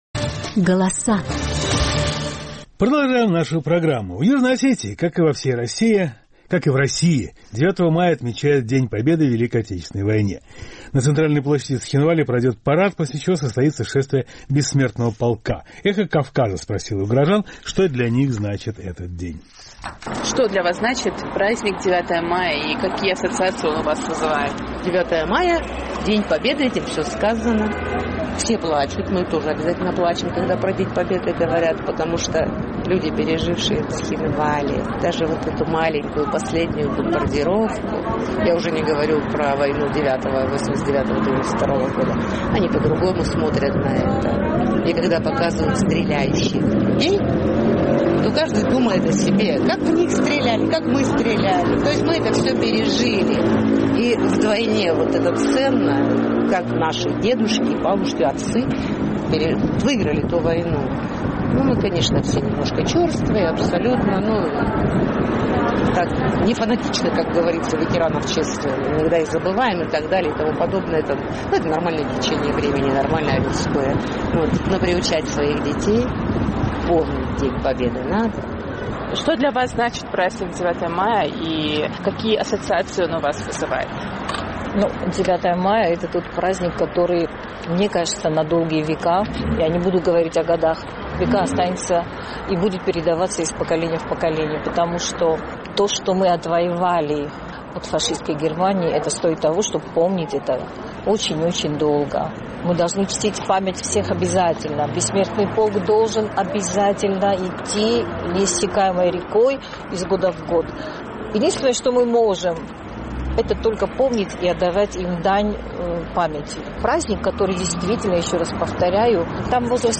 «Эхо Кавказа» спросило у горожан, что для них значит этот день и с чем он ассоциируется.